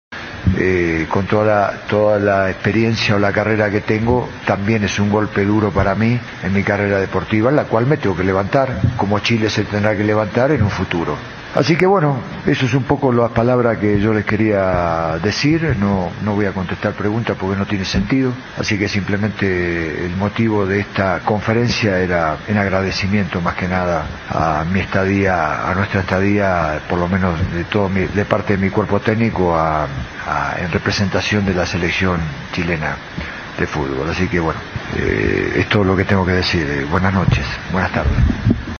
Terminado el partido, el entrenador se despidió de los jugadores y en la conferencia de prensa agradeció a la dirigencia, ya que aseguró que siempre sintió su apoyo.